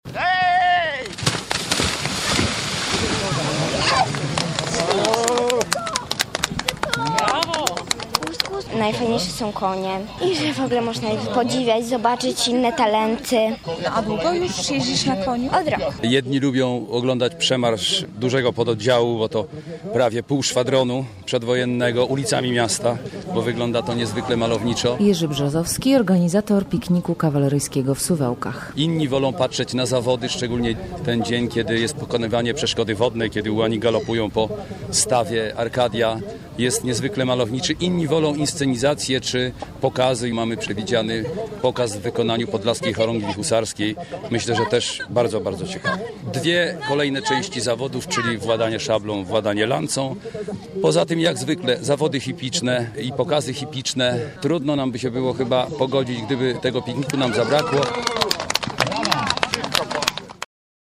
Piknik Kawaleryjski w Suwałkach